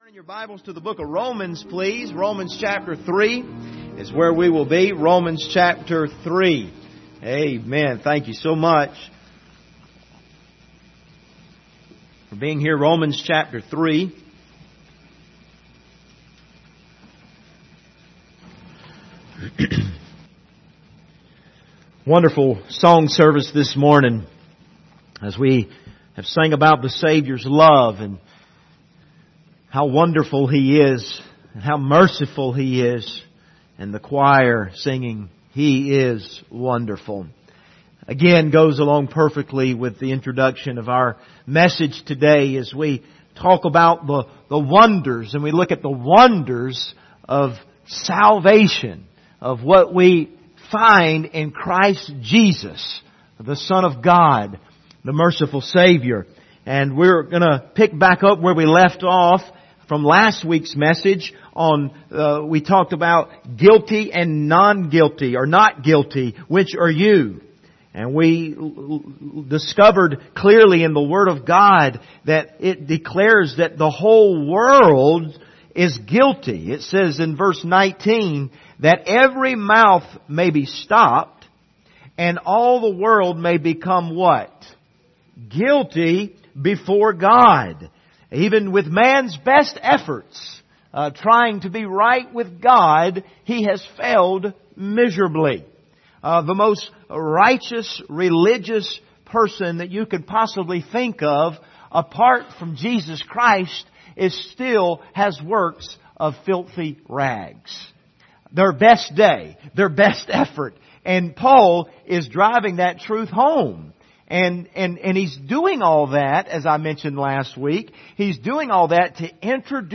Passage: Romans 3:21-31 Service Type: Sunday Morning